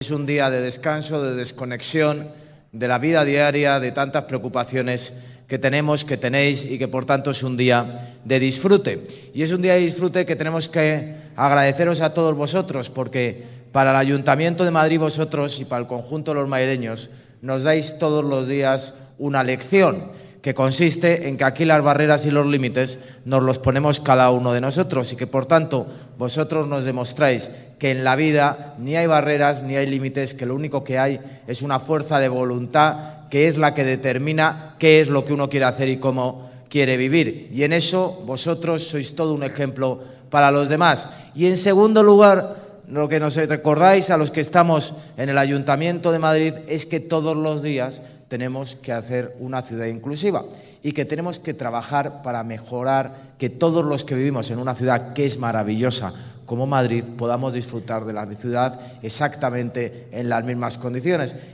Hoy “es un día de descanso, de desconexión de la vida diaria, de tantas preocupaciones y, por tanto, es un día de disfrute”, resumía el alcalde de Madrid en la apertura de sus palabras de bienvenida a esas más de 1.200 personas reunidas dentro y fuera del auditorio.